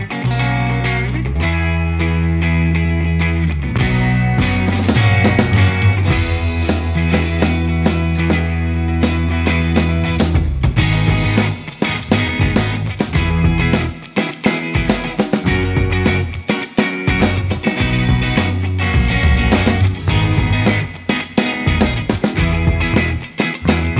no Backing Vocals Indie / Alternative 3:23 Buy £1.50